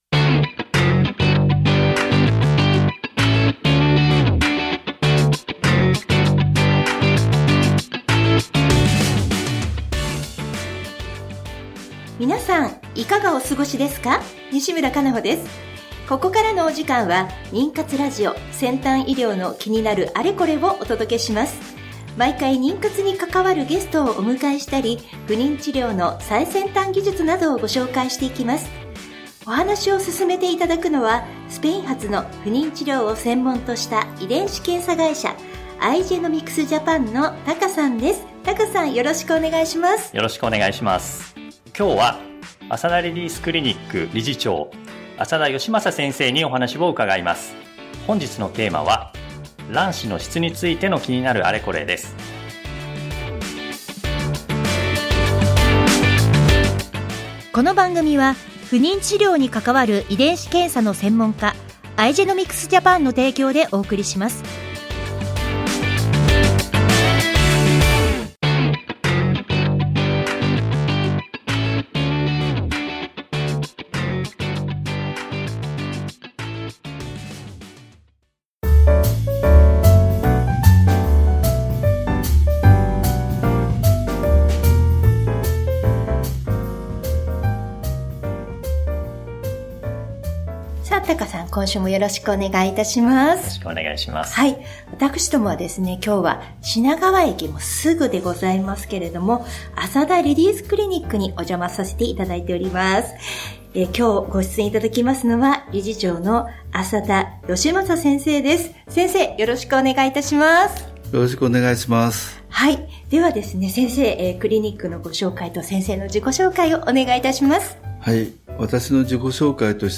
ナビゲーターはフリーアナウンサー